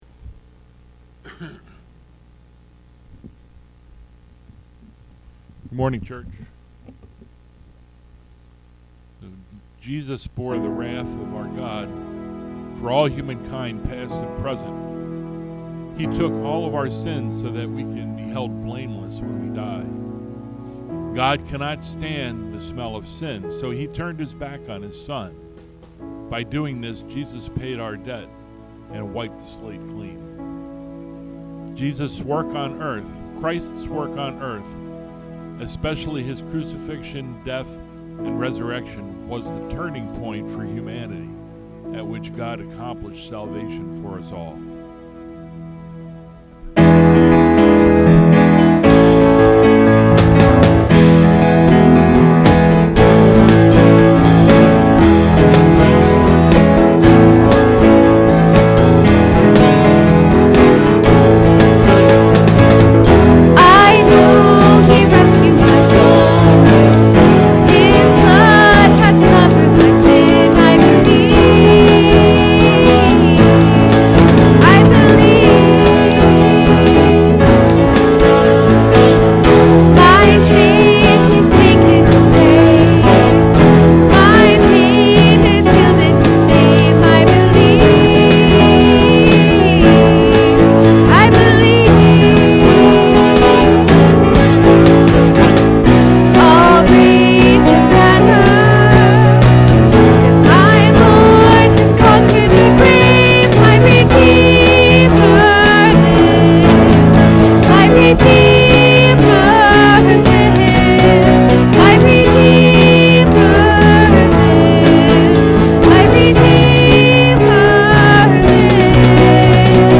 Series: Sunday Morning Worship Service